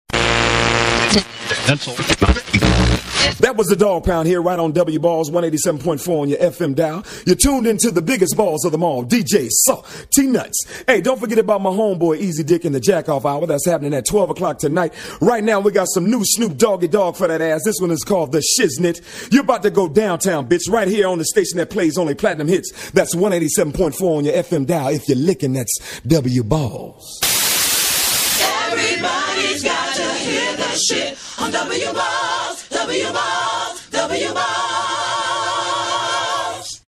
Skit
(Radio Static)